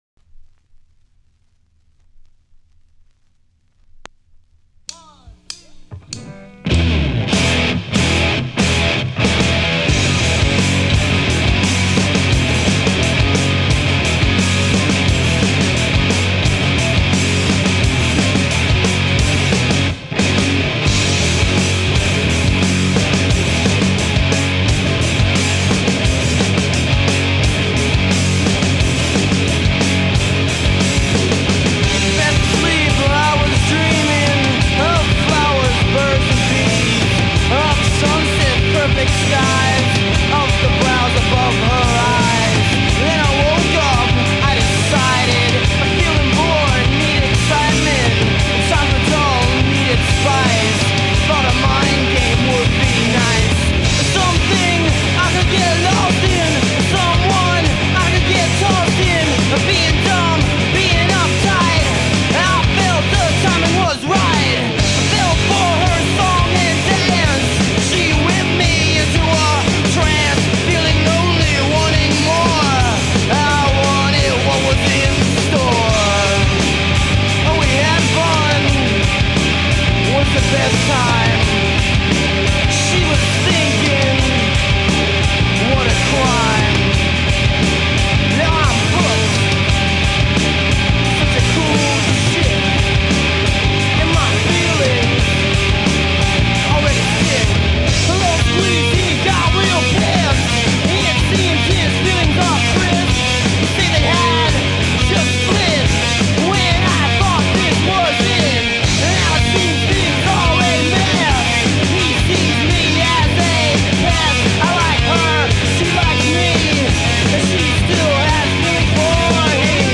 brutal, bone-rattling rock and roll
guitar
bass